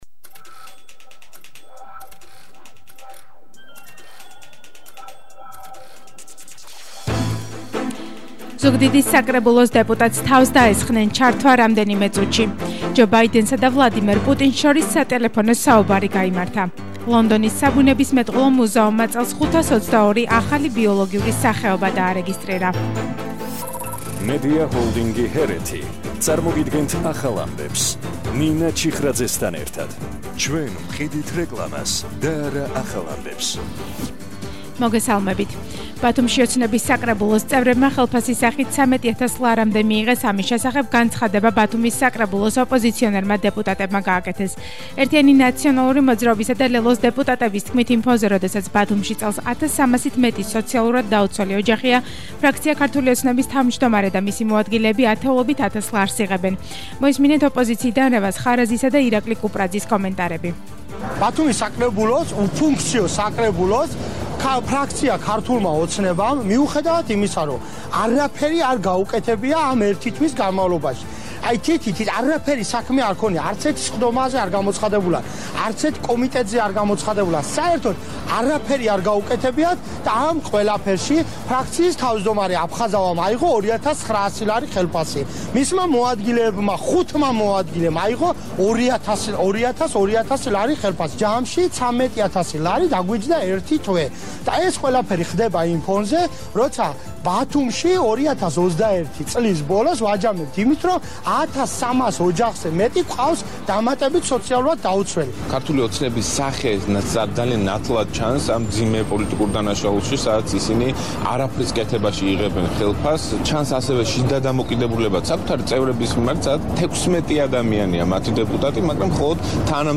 ახალი ამბები 14:00 საათზე – 31/12/21 – HeretiFM